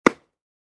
Tennis Ball Hit Sound Effect
Tennis-ball-hit-sound-effect.mp3